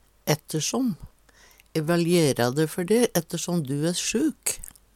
ættersom - Numedalsmål (en-US)